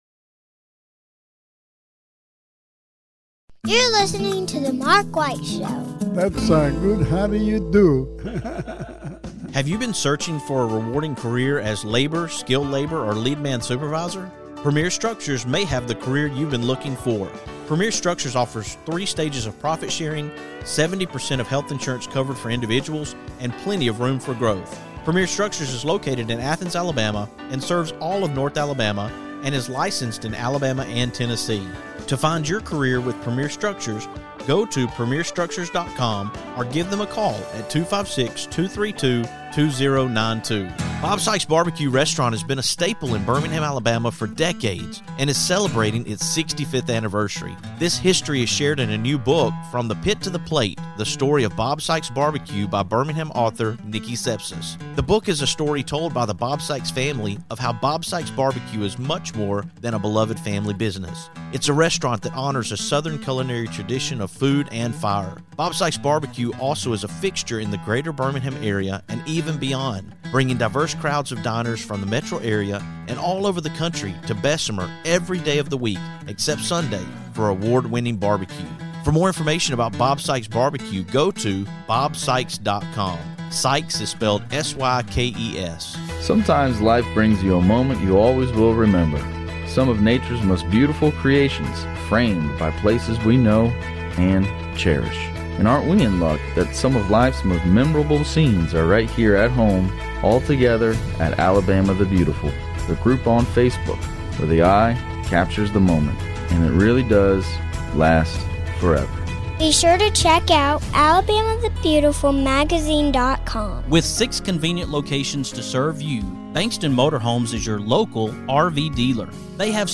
I hope you will listen and share my conversation